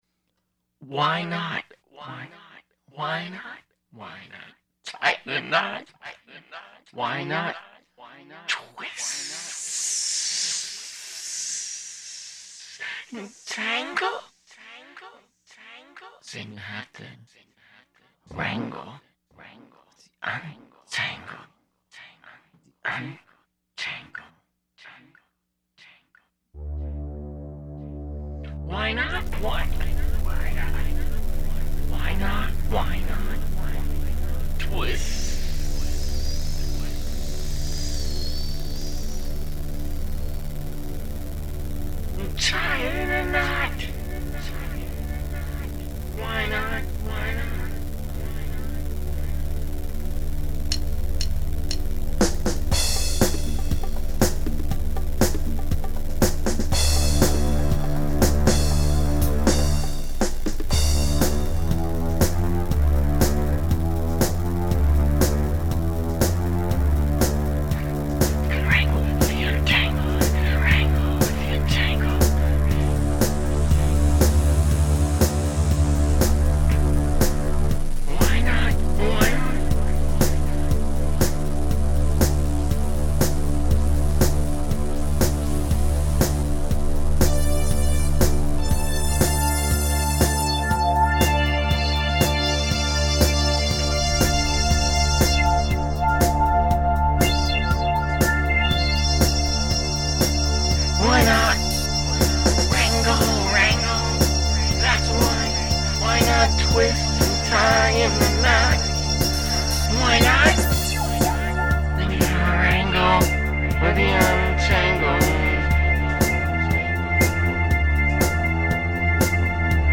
Dm
Em Em F
Vocals, Keyboards, Synthesizers, Controllers and a Computer